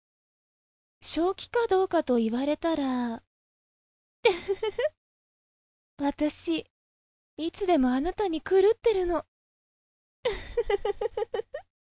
成人女性～初老の女性の低めの音域が得意です。